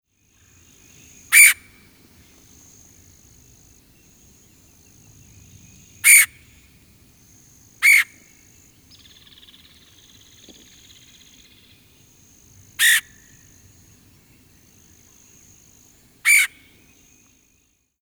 Red-headed Woodpecker
BIRD CALL: LOUD, HIGH-PITCHED “CHURR” OR “QUEEAR”; ALSO DRUM ON TREES AND OTHER TALL STRUCTURES AS TERRITORY DEFENSE AND PAIR FORMATION.
Red-headed-woodpecker-call.mp3